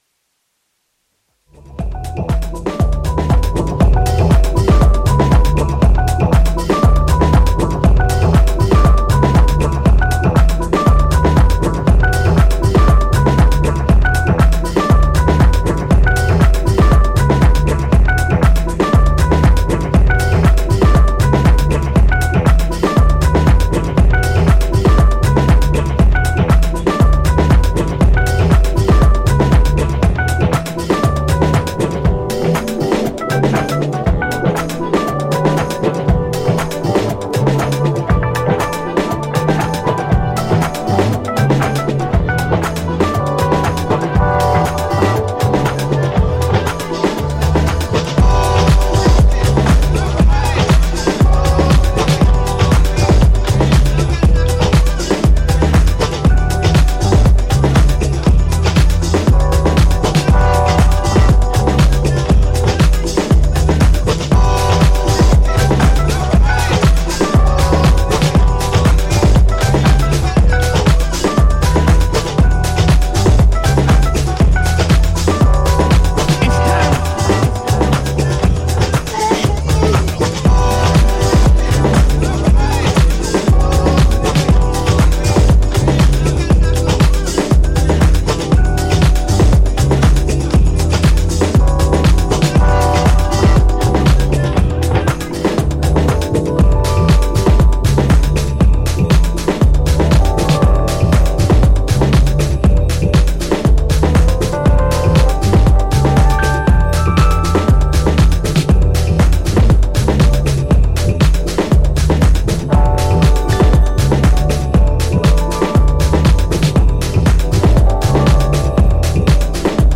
ジャンル(スタイル) DISCO / NU DISCO